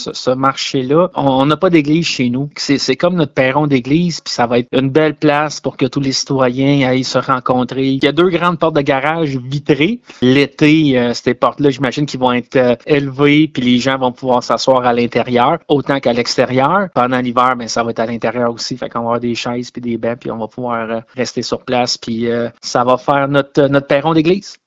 En entrevue, le maire, Sylvain Laroche, a expliqué pourquoi la réalisation de ce projet est si importante pour sa communauté.